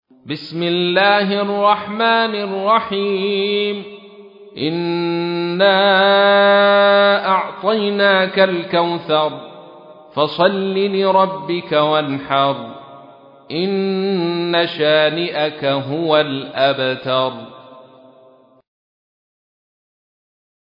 تحميل : 108. سورة الكوثر / القارئ عبد الرشيد صوفي / القرآن الكريم / موقع يا حسين